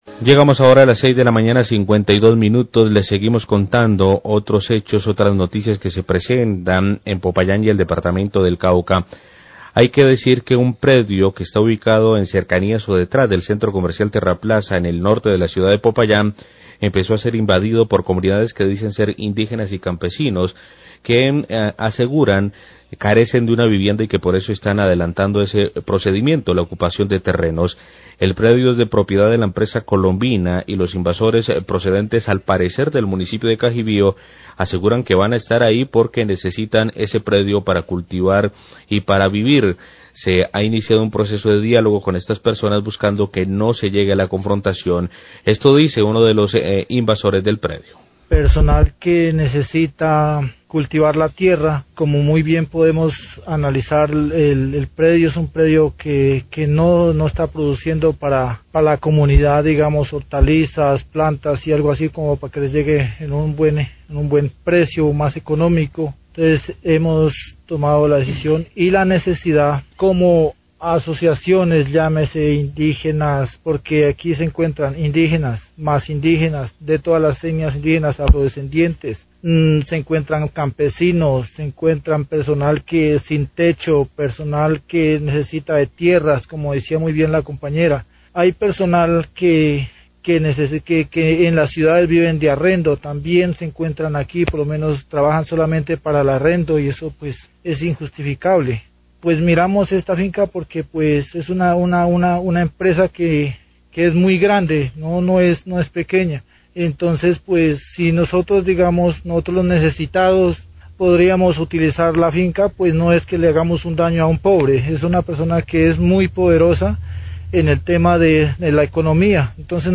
Sria de Gobierno de Popayán e invasor de terreno de Colombian hablan sobre este acción de invasión
Radio
Algunas personas procedentes de Cajibio, invadieron un predio aledaño al Centro Comercial Terraplaza en Popayán y que es propiedad de Colombina. Habla uno de los invasores explicando las razones que los motivaron a realizar esta acción ilegal.